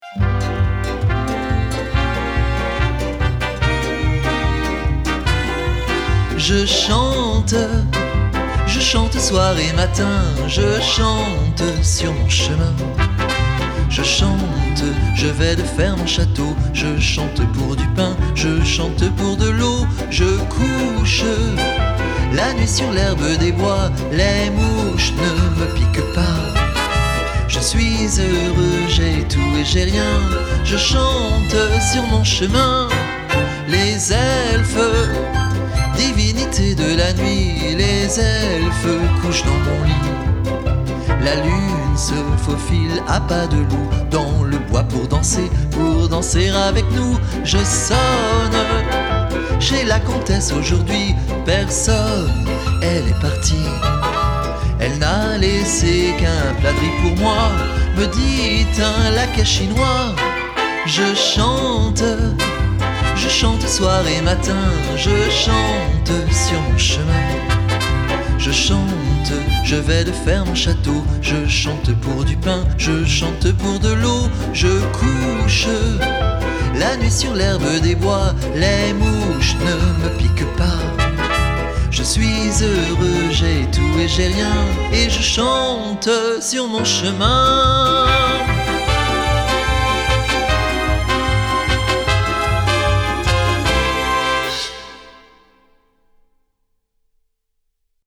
La version chantée